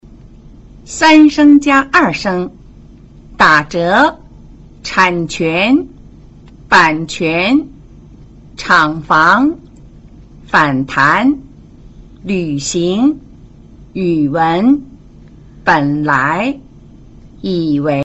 在語流中,第三聲詞往往讀成半三聲,也就是只讀音節聲調的降調部份。